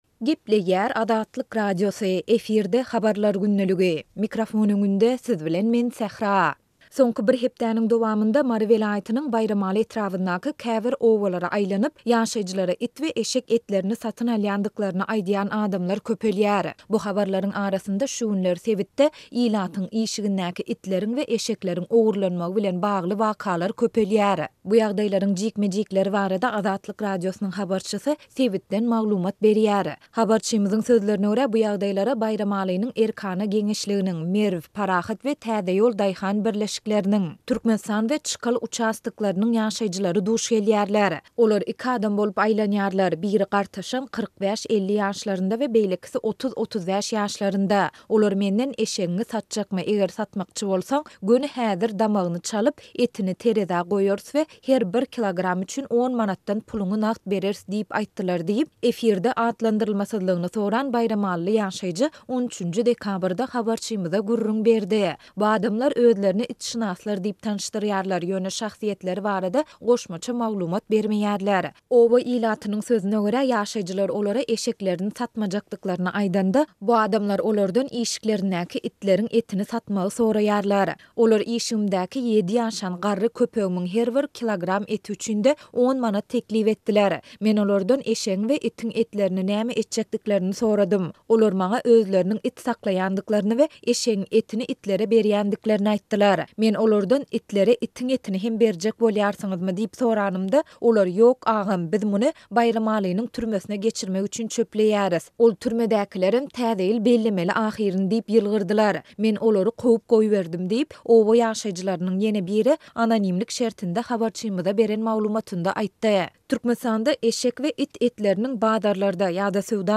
Bu ýagdaýlaryň jikme-jiklikleri barada Azatlyk Radiosynyň habarçysy sebitden maglumat berýär.